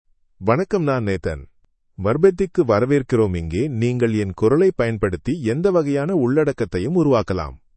Nathan — Male Tamil AI voice
Nathan is a male AI voice for Tamil (India).
Voice sample
Listen to Nathan's male Tamil voice.
Male
Nathan delivers clear pronunciation with authentic India Tamil intonation, making your content sound professionally produced.